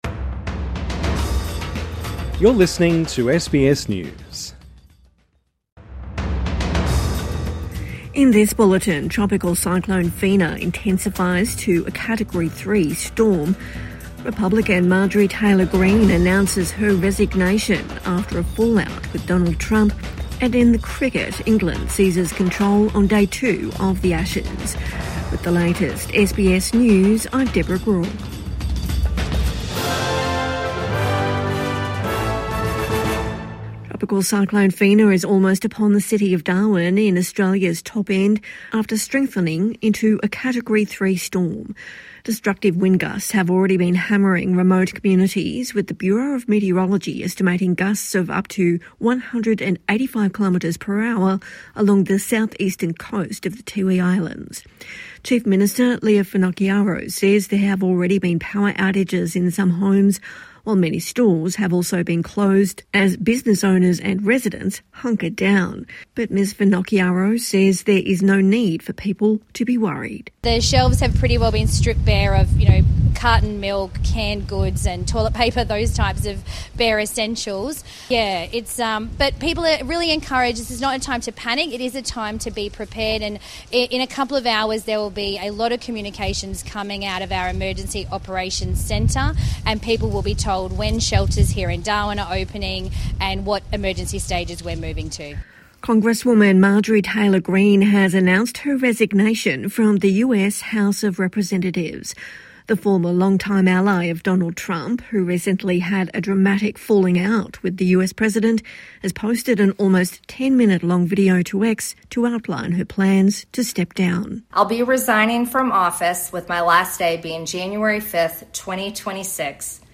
Marjorie Taylor Greene quits Congress | Evening News Bulletin 22 November 2025